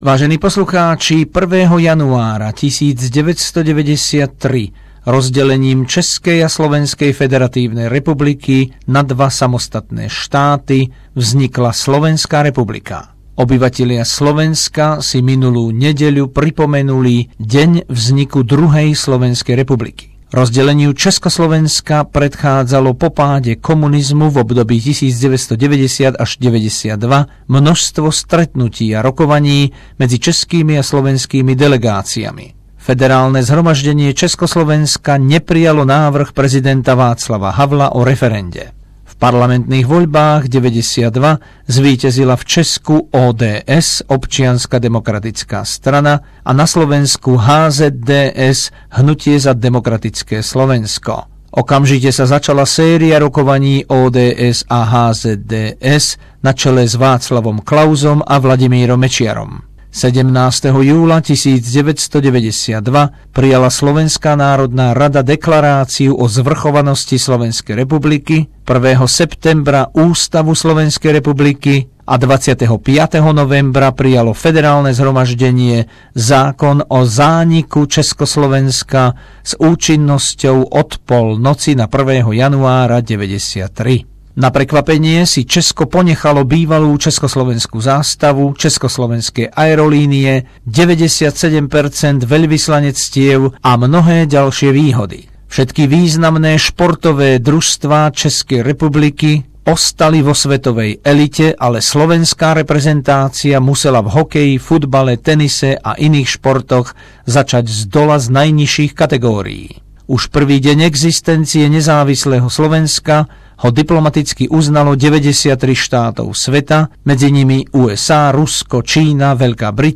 História vzniku povojnovej Slovenskej republiky roku 1993 a novoročný príhovor nášho veľvyslanca v Austrálii Igora Bartha ku krajanom